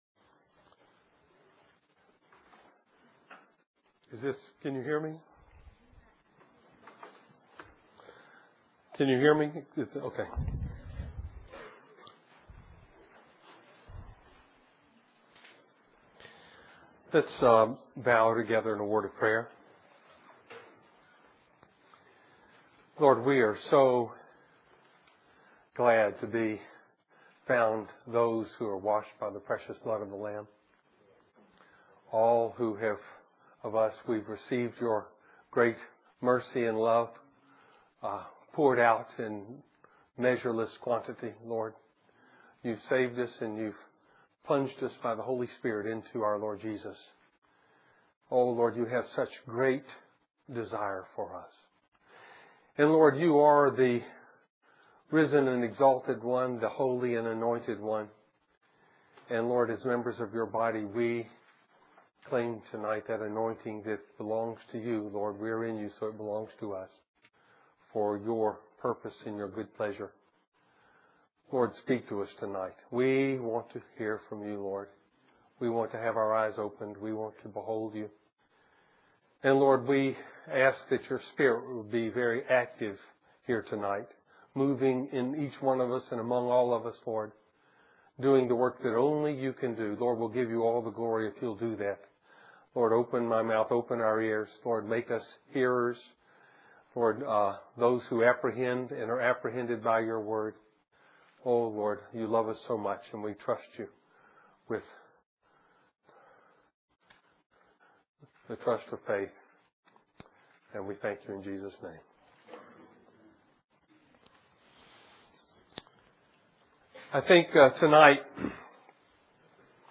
2005 Winter Youth Conference Stream or download mp3 Summary No little child can become a young man, in the Lord, unless they lay down their life, and take up warfare and intercession.